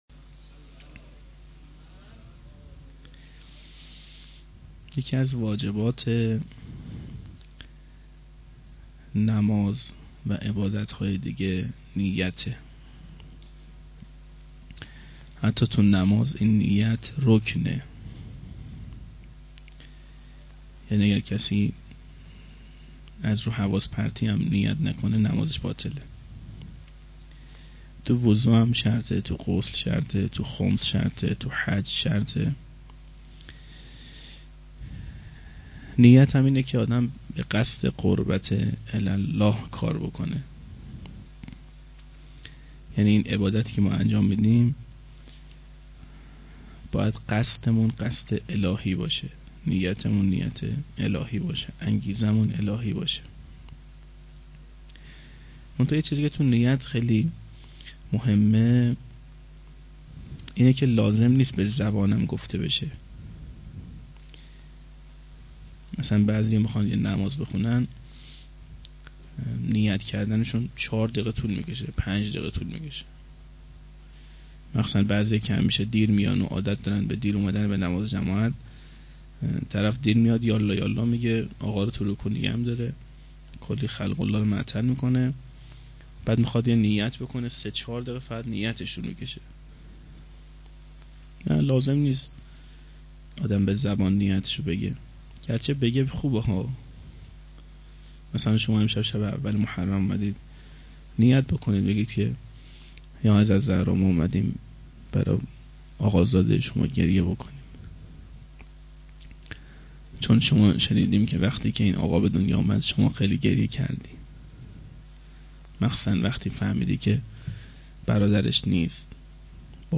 01-shab1-sokhanrani.mp3